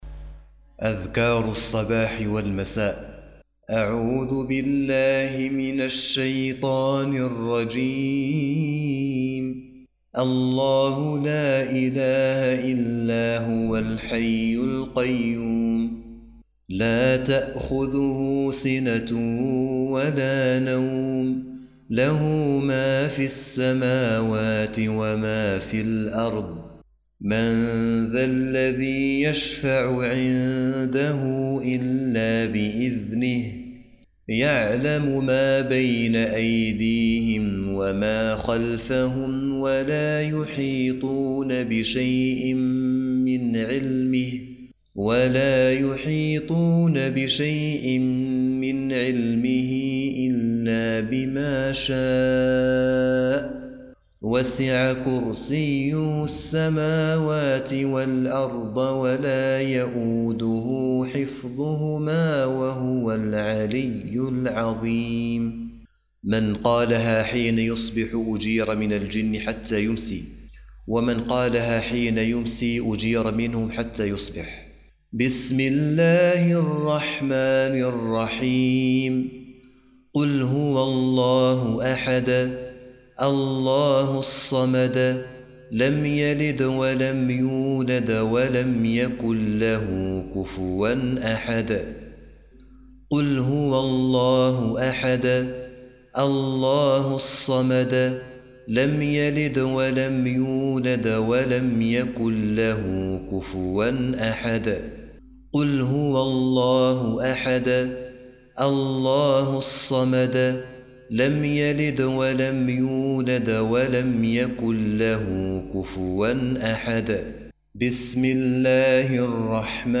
تلاوة مريحة لأذكار الصباح والمساء